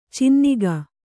♪ cinniga